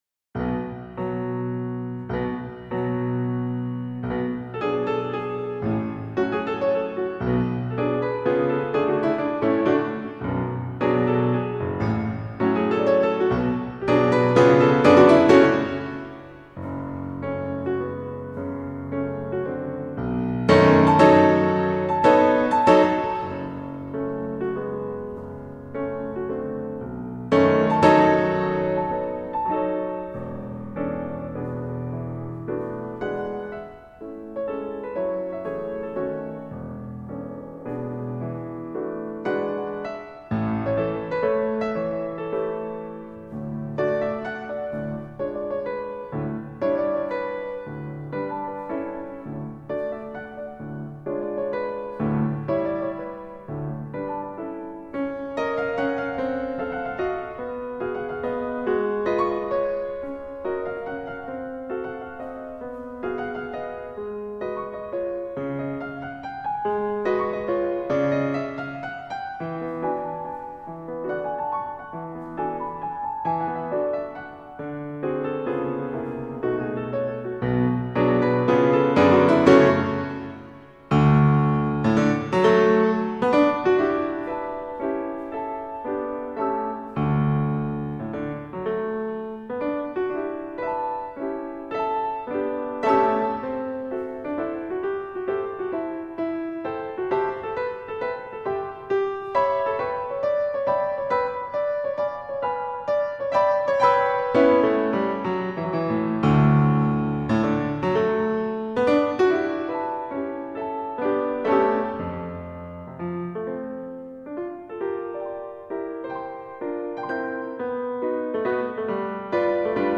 Piano  (View more Intermediate Piano Music)
Classical (View more Classical Piano Music)